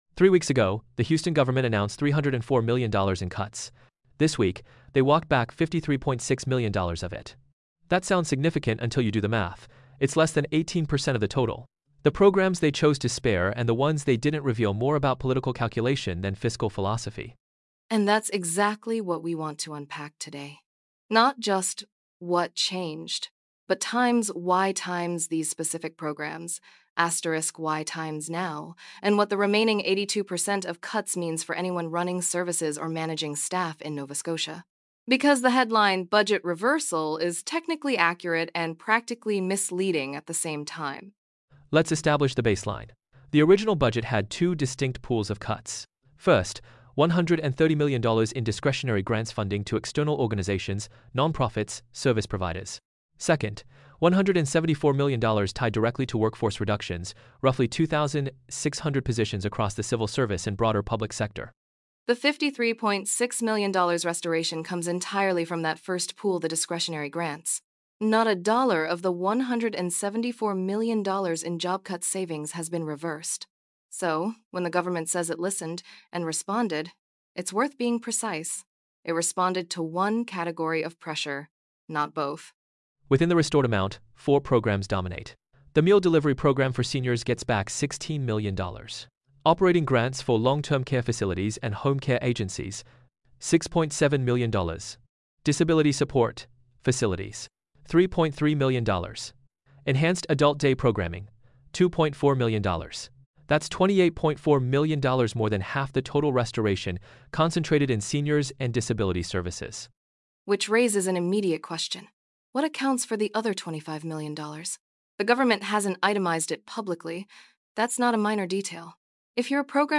▶ Listen to the updated audio sample: Download MP3 This iteration improved pacing, speaker contrast, and overall listenability versus the first pass. The pipeline itself is stable; most gains now come from final-mile quality tuning (voice pairing, spoken-text cleanup, and quick probe checks before full renders).
cbc-budget-article-sonnet-dual-final-voice1-v2.mp3